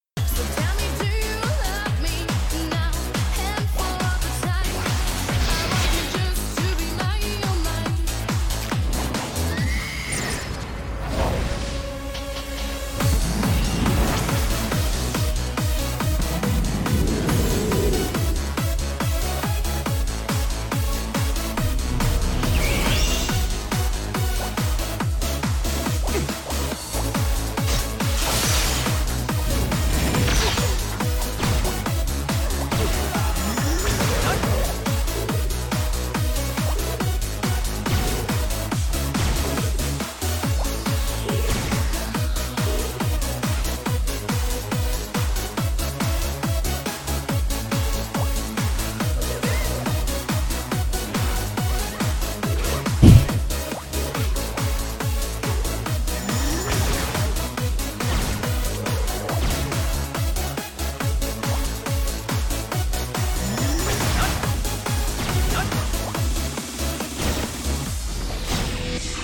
Proszę o pomoc nazwa tej piosenki - Muzyka elektroniczna
Mam tylko urywek zgrany z transmisji może ktoś wie co to za piosenka?